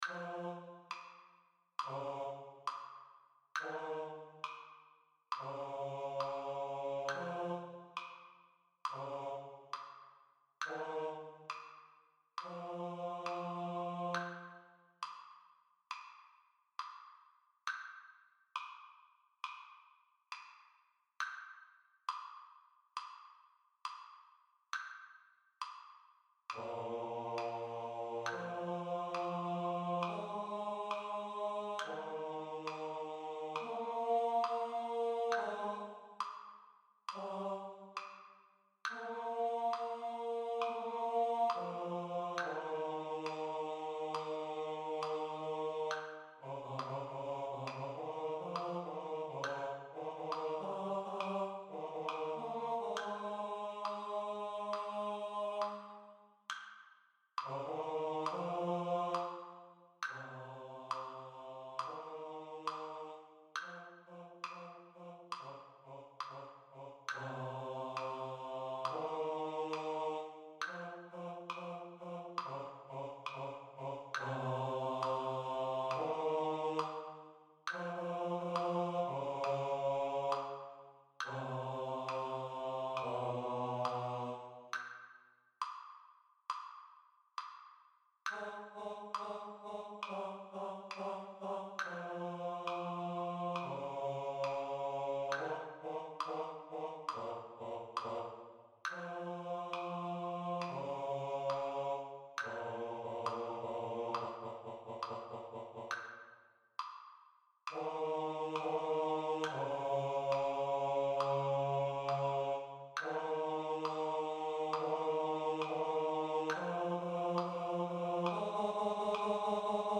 Let-It-Go-Bass | Ipswich Hospital Community Choir